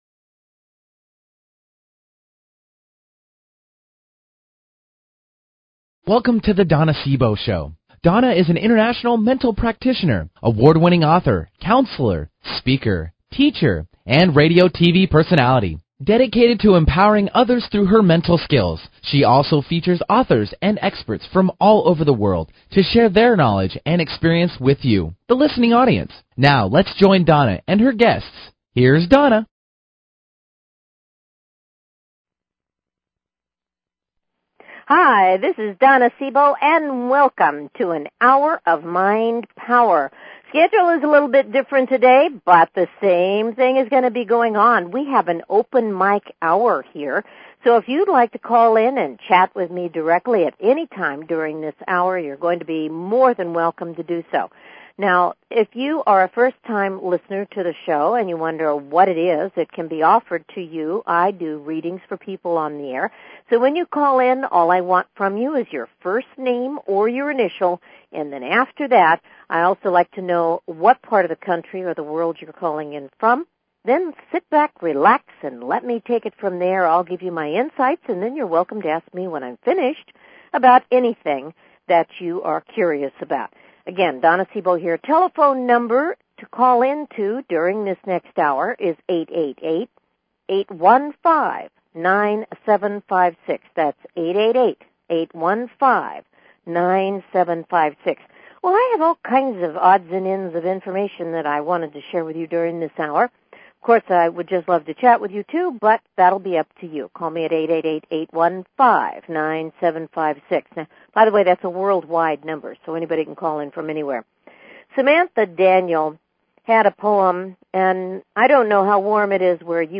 Talk Show Episode, Audio Podcast
You can call in at any time during the show and get a reading.
Callers are welcome to call in for a live on air psychic reading during the second half hour of each show.